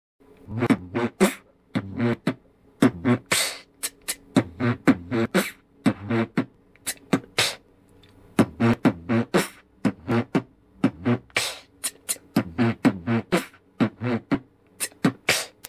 b wb b wb pf/ b wb b/ b wb kch t t/
b wb b wb pf/ b wb b/ t b kch..
Простой бит, заеженный до дыр.